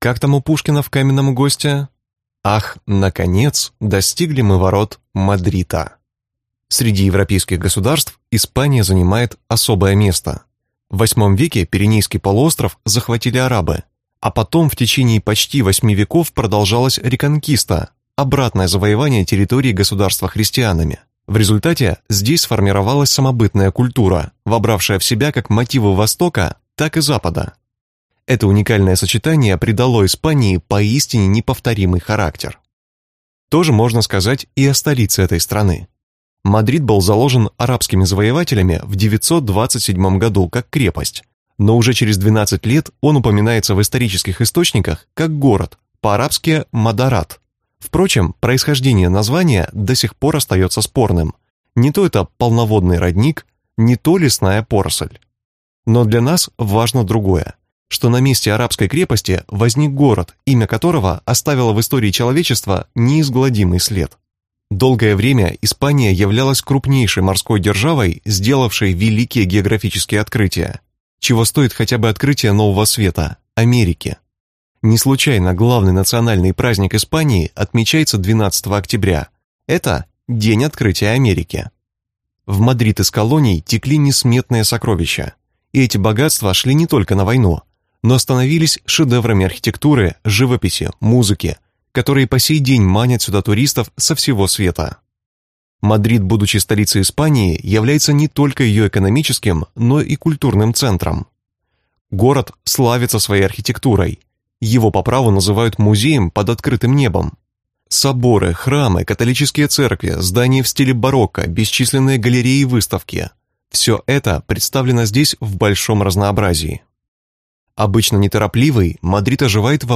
Аудиокнига Мадрид. 10 мест, которые вы должны посетить | Библиотека аудиокниг